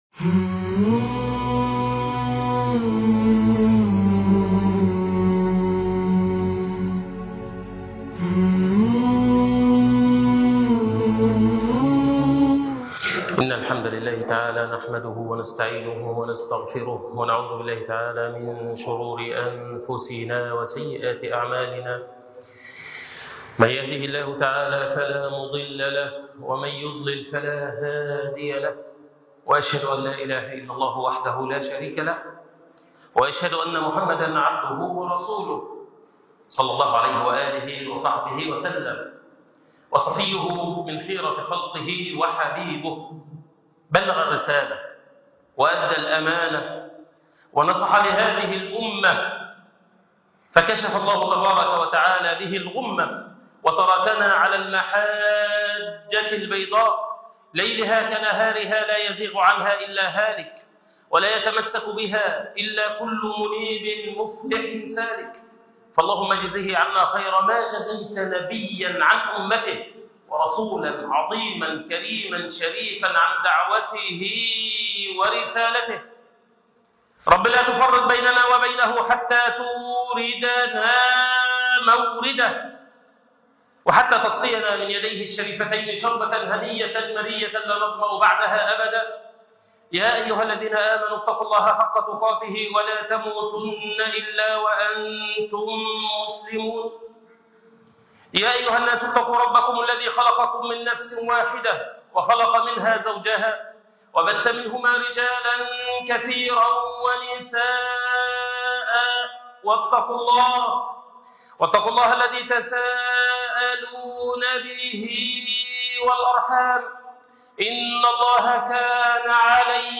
يوم أظلمت الأرض _خطبة جمعة